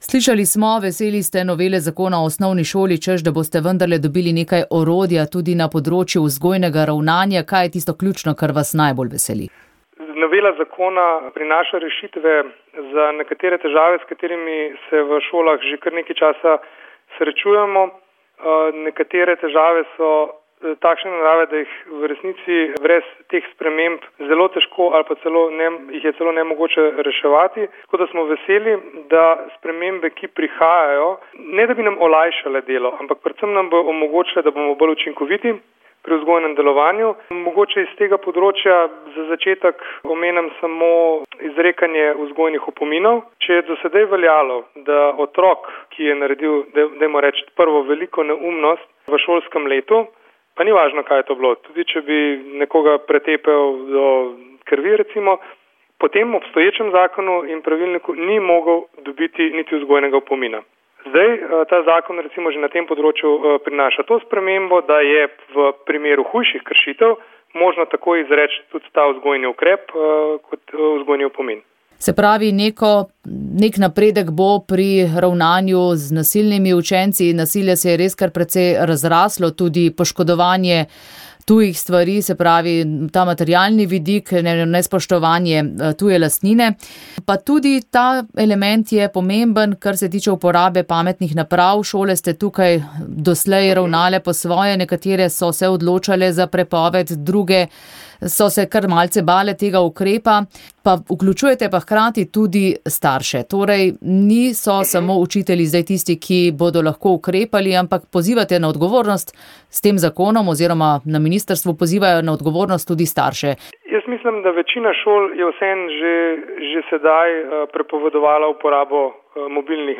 V predsedniški palači je ponedeljek potekal sprejem v počastitev te obletnice. Govorniki so izpostavili pomen dialoga med strankami za to, da je prišlo do osamosvojitve.
V prispevku lahko prisluhnete nekaj njunim poudarkom, in poudarku predsednika države Pahorja, nato pa še pogovoru s poslancem Demosa Antonom Tomažičem.